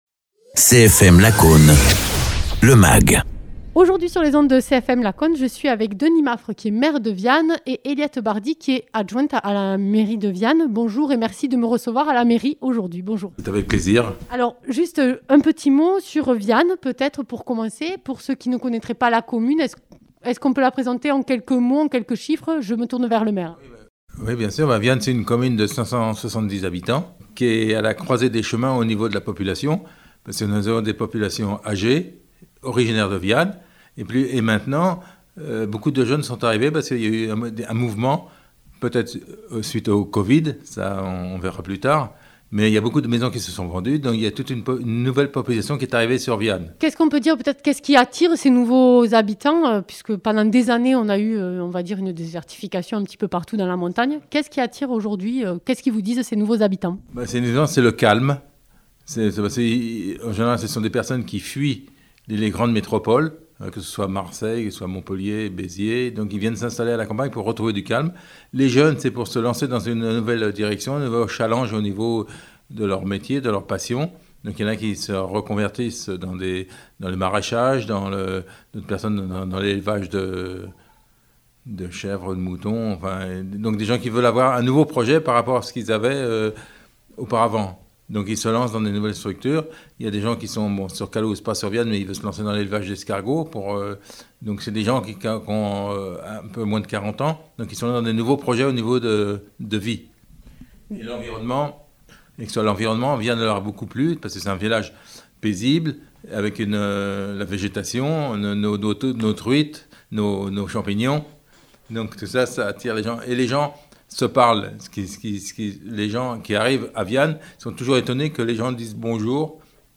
Interviews
Invité(s) : Denis Maffre, maire de Viane (Tarn) ; Eliette Bardy, adjointe de la commune de Viane.